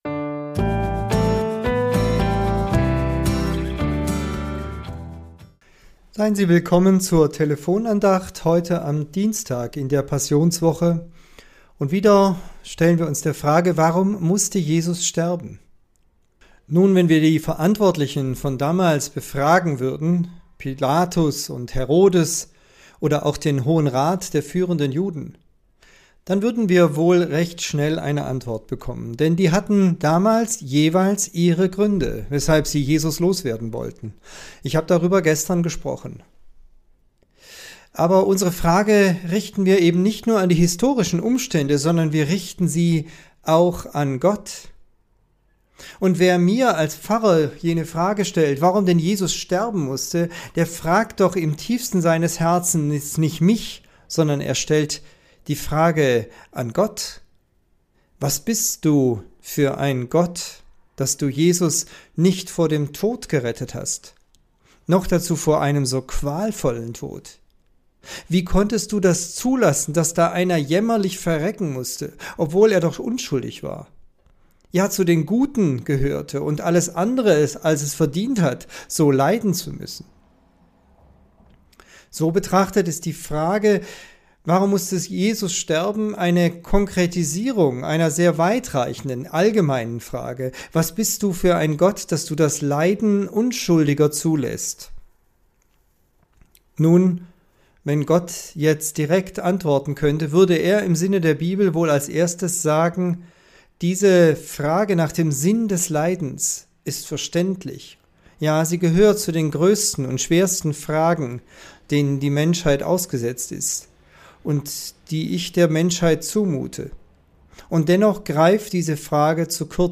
Tübinger Telefonandacht zur Tageslosung